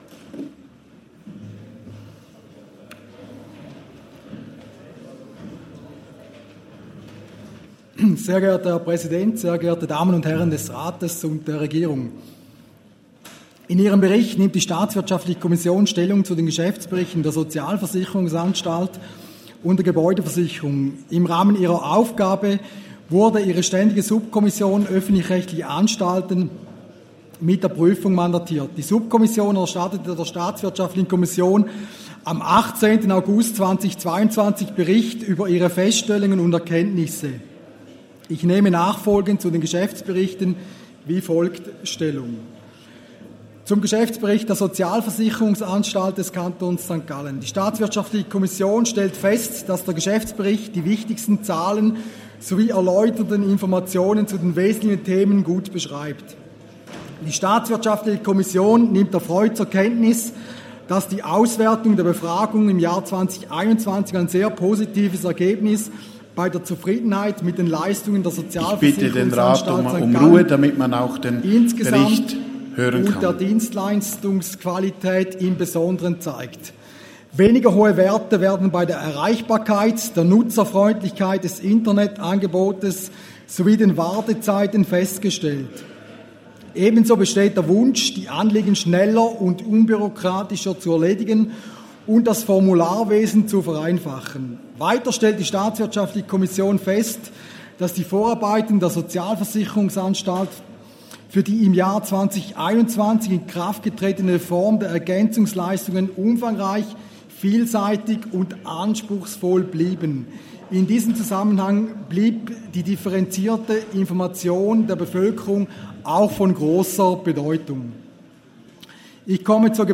Session des Kantonsrates vom 28. bis 30. November 2022